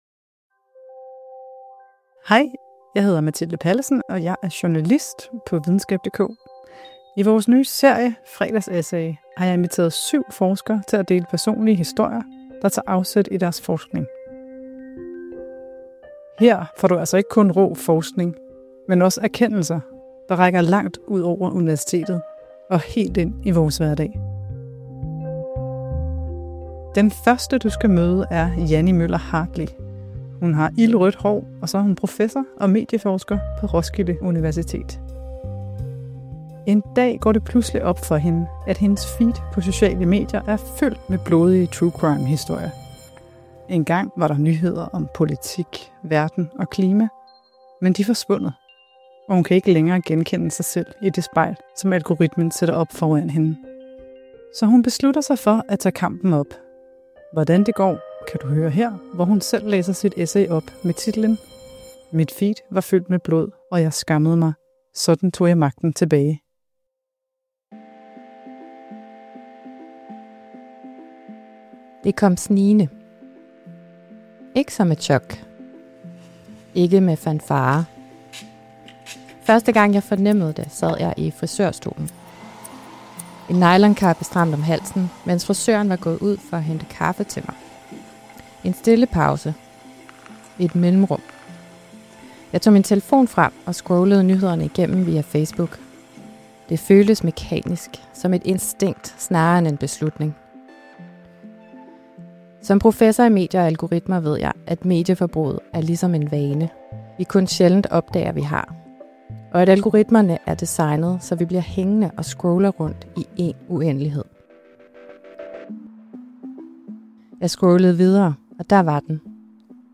Her kan du høre forskeren selv læse sit essay op.